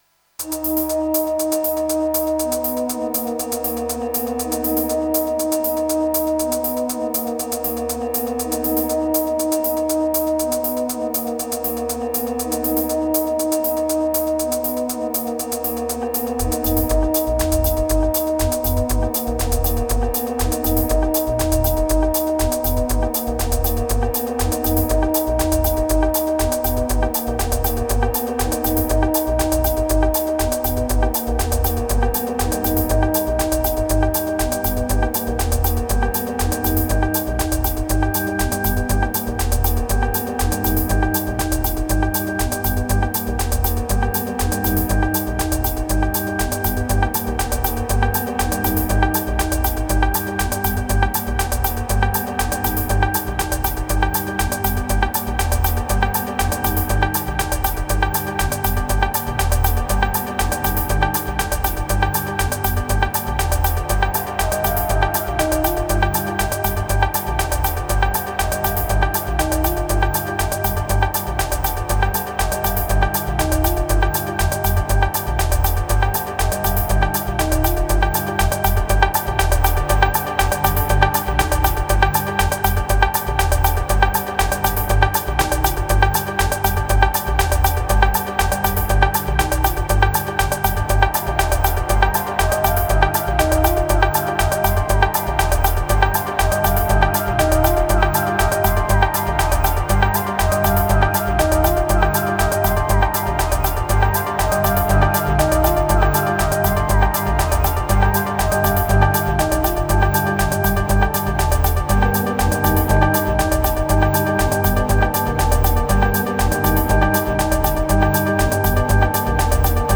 984📈 - 95%🤔 - 120BPM🔊 - 2024-07-17📅 - 708🌟
Electro Synthesizer Analog Ambient Moods Cognitive Ladder